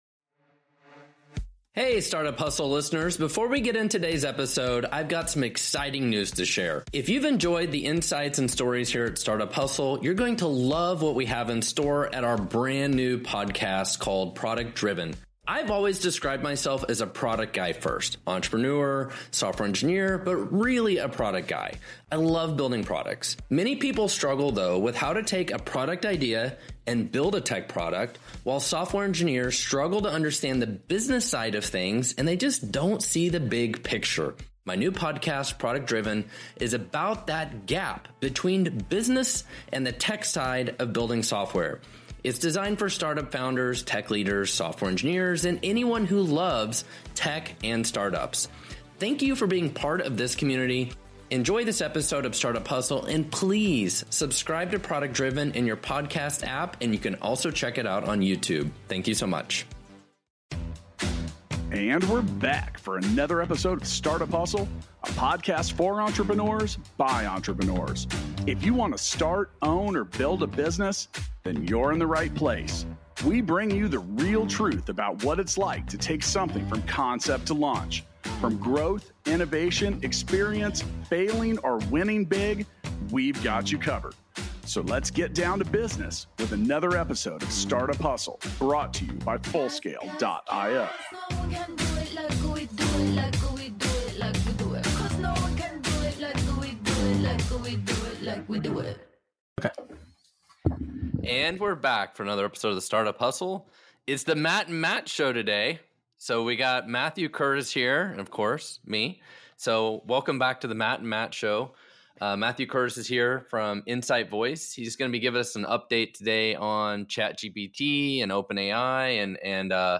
Enjoy an engaging conversation about the latest developments in ChatGPT, navigating the path to product-market fit, and the evolving landscape of AI. You'll hear insightful discussions on the commoditization of AI and the ethical considerations surrounding artificial intelligence. Gain valuable perspectives on the intricacies of startup growth, effective product marketing strategies, the quest for market fit, and the enduring rewards of content creation and business building.